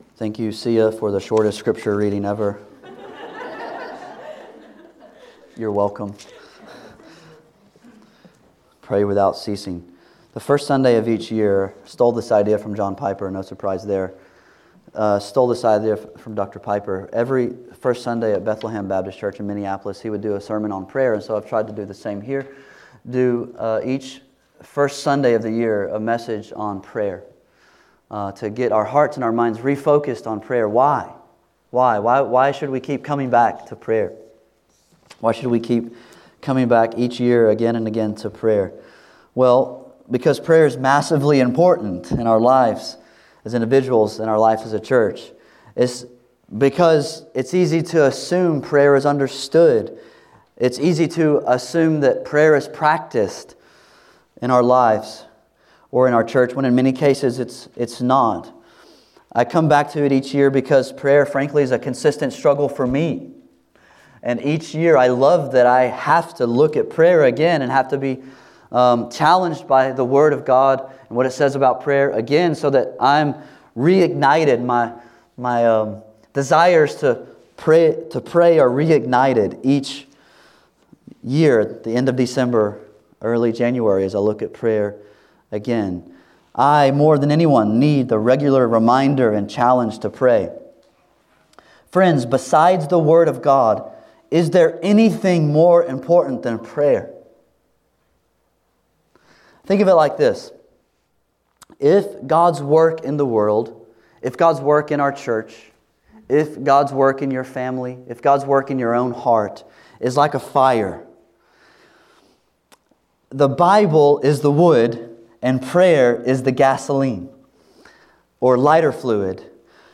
The Necessity of Prayer On the first Sunday of the year I do a sermon on prayer.